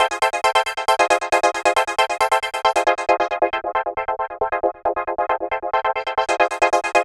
Lead 136-BPM F.wav